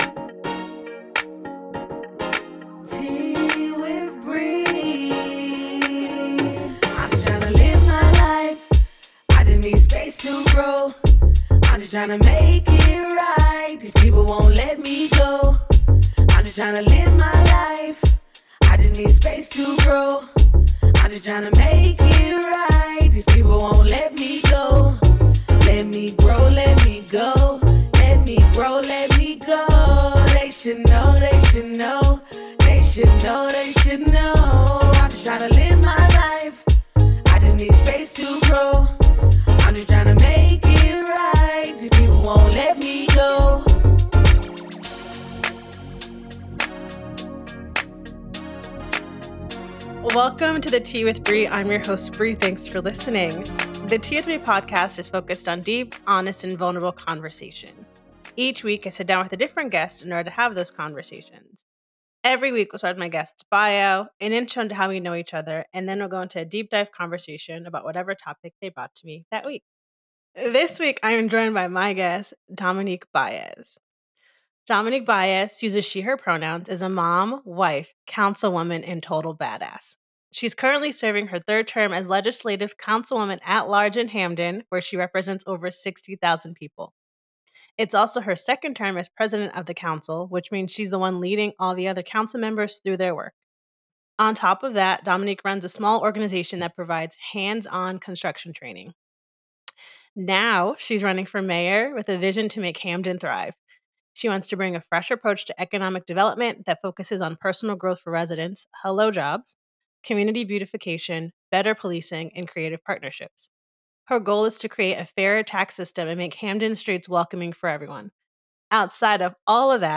----- This podcast was recorded via Riverside FM.